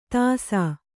♪ tāsā